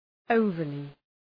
Προφορά
{‘əʋvərlı}